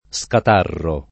[ S kat # rro ]